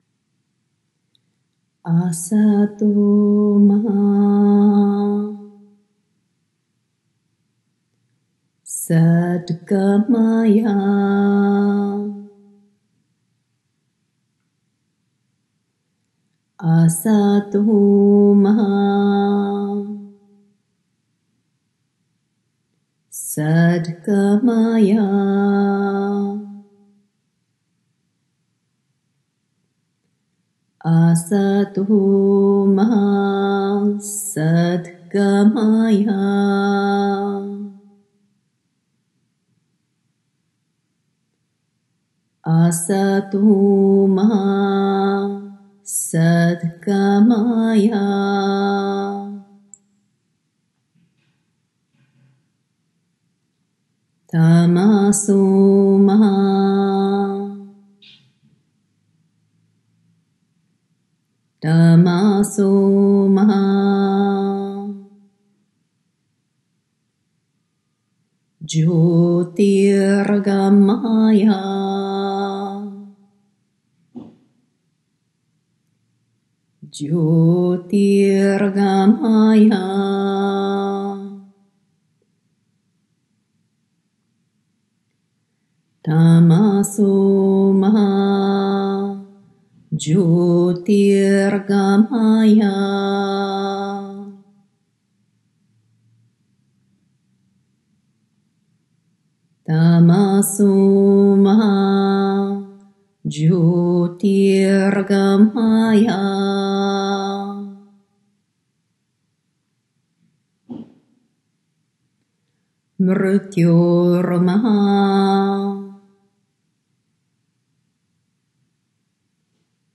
Chanting as Meditation Practice